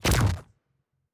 pgs/Assets/Audio/Sci-Fi Sounds/Weapons/Weapon 10 Shoot 1.wav at master
Weapon 10 Shoot 1.wav